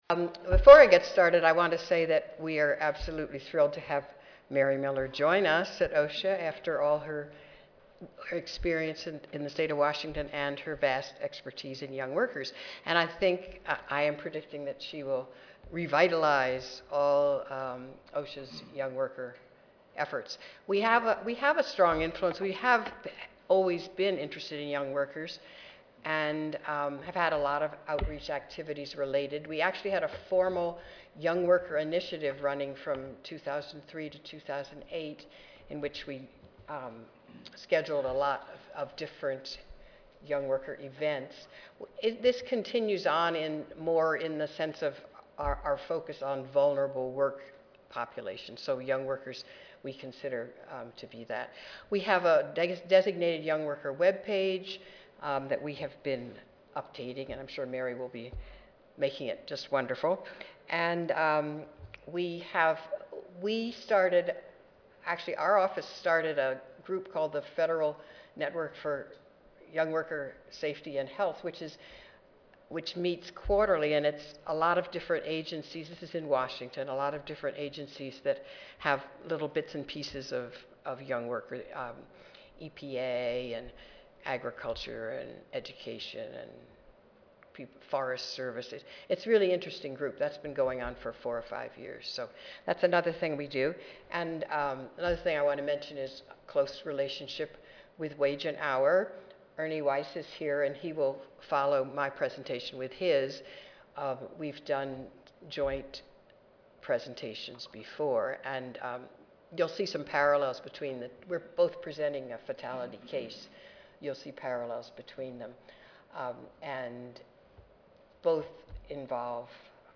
Oral Session